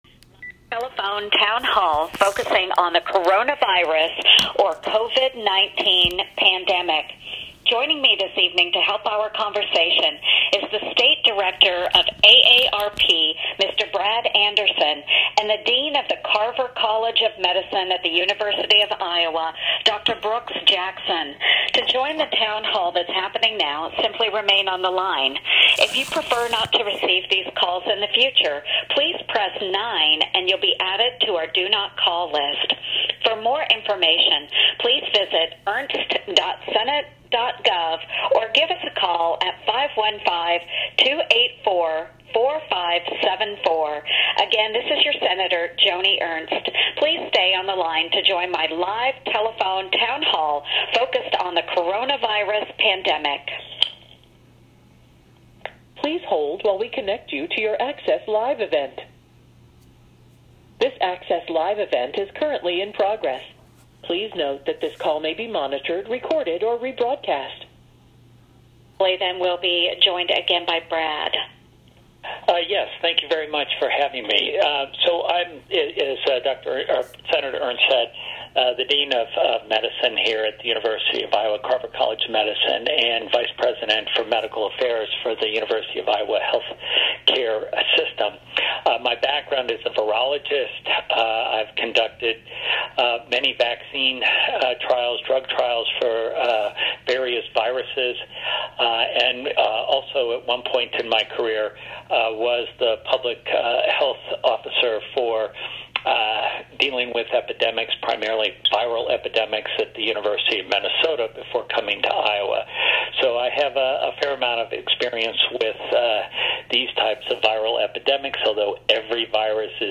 An Iowan who listened in provided this recording to Bleeding Heartland.